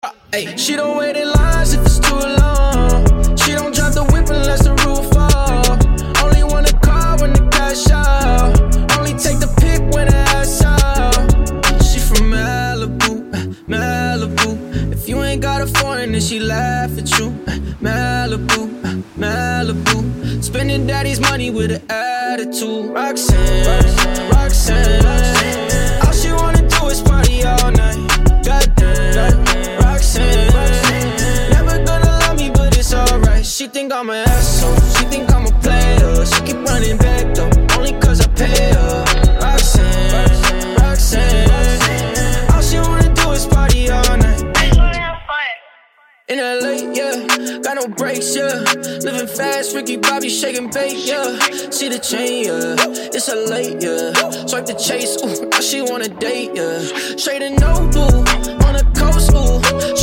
Up 3 Semitones Pop (2010s) 3:47 Buy £1.50